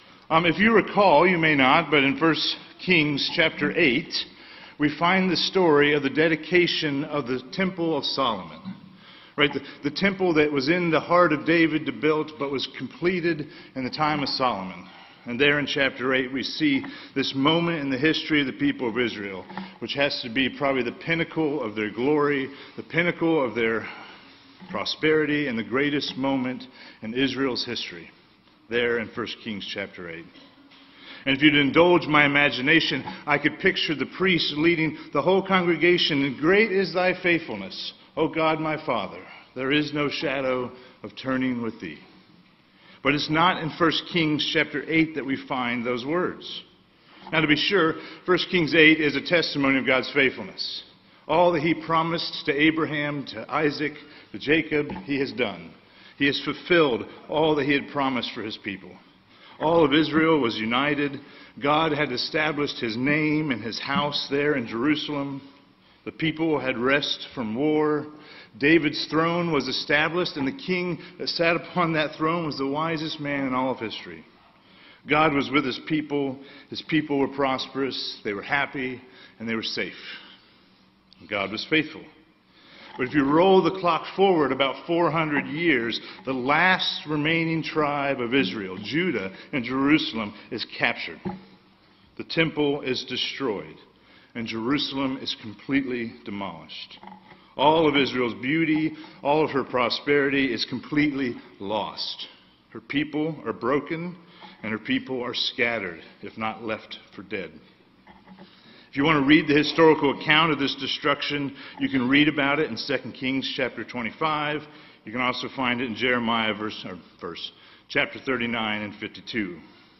Sermon preached
2024 at First Baptist Church in Delphi, Indiana.